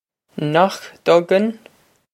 Pronunciation for how to say
Nohk dug-in?
This is an approximate phonetic pronunciation of the phrase.